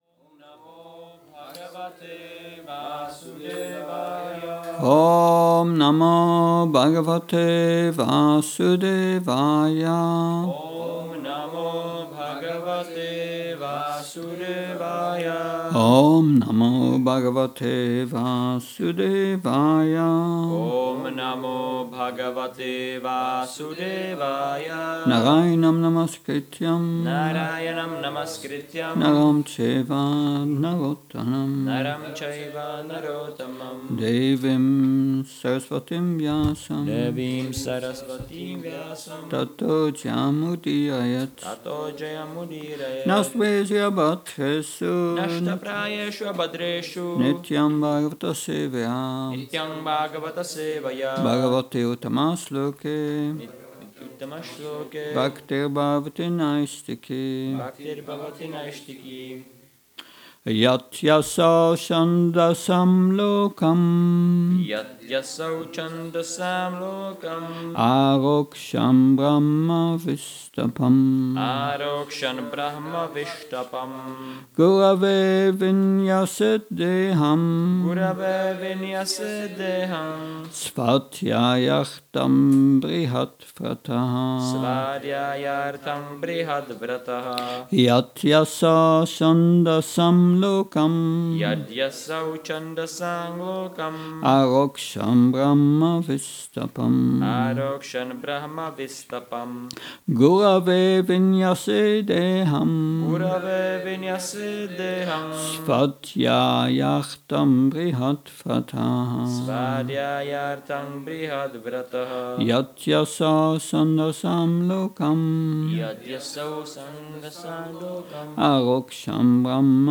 Přednáška SB-11.17.31